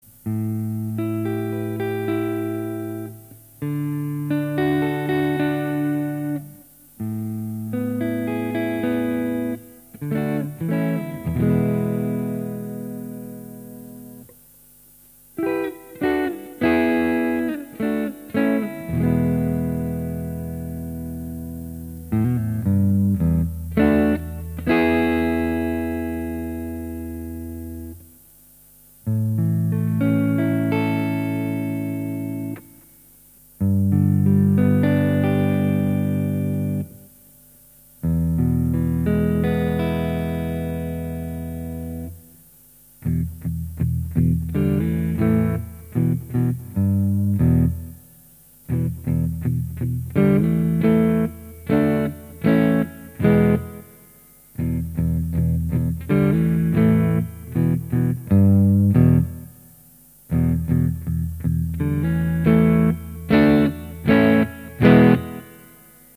Ghost Rider alnico 5 humbucker, filtertron and classic huimbucker tones
Tapped on both sides, the winding matches that of a typical Filtertron, just placed on a full sized humbucker frame and bobbins. It also has the double thickness (1/2") Alnico 5 magnet needed to get the proper amount of sizzle and pop. When used full, it sounds very much like the Vampire Lord but with some extra aggression.